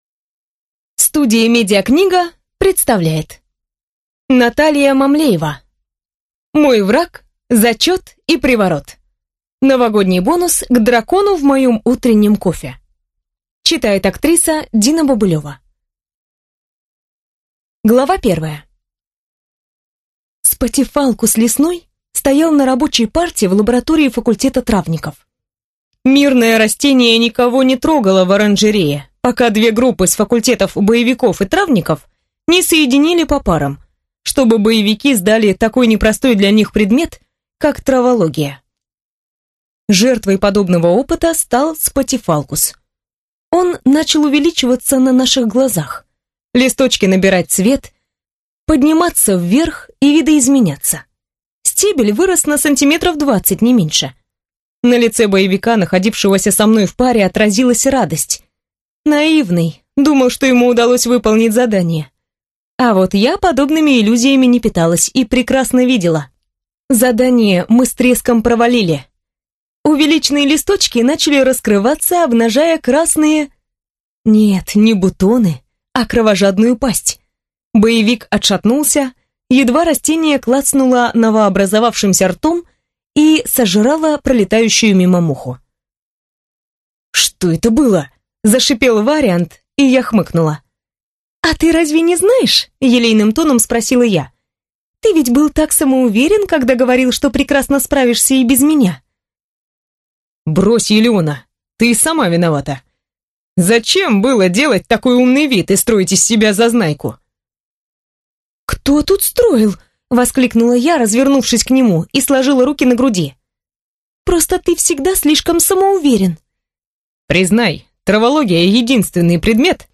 Аудиокнига Мой враг, зачет и приворот | Библиотека аудиокниг
Прослушать и бесплатно скачать фрагмент аудиокниги